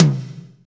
Index of /90_sSampleCDs/Northstar - Drumscapes Roland/TOM_Toms 1/TOM_F_S Toms x
TOM F S H0HR.wav